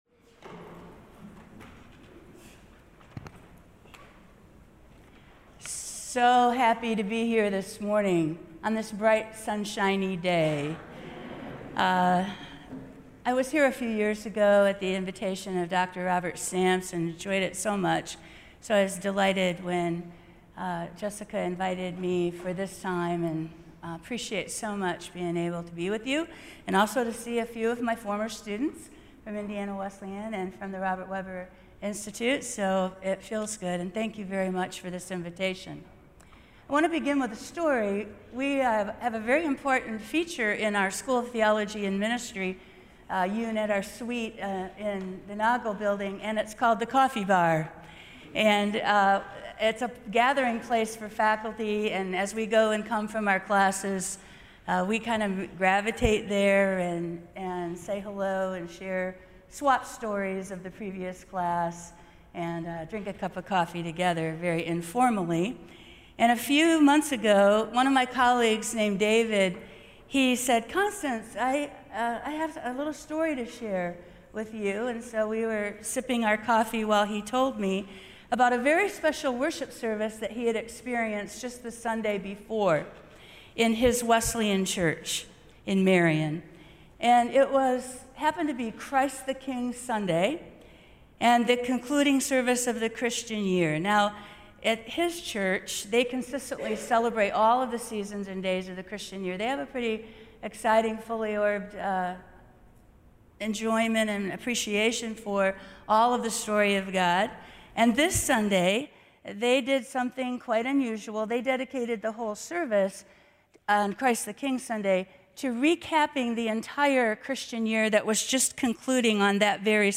Series Chapel services, 2020
sermons